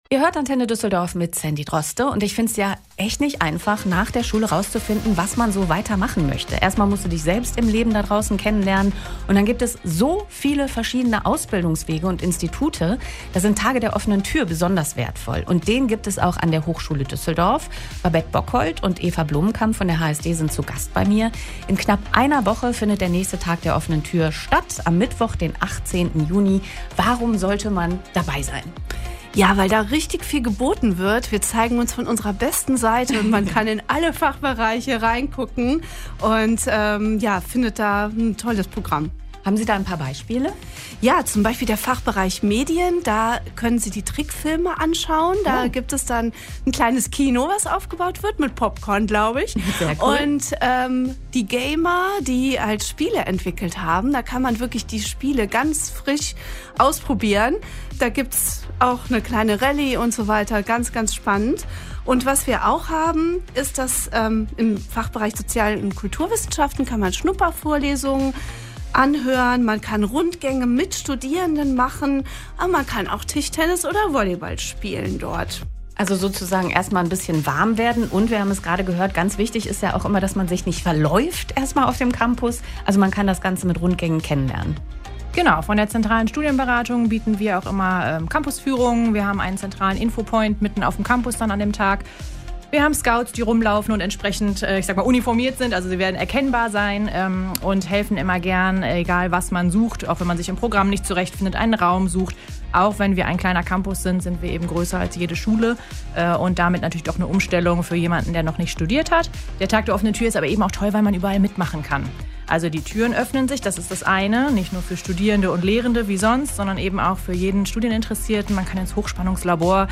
Das HSD-Team war am 12.06.2025 bei Antenne Düsseldorf zu Gast und hat einiges über den Tag der offenen Tür erzählt.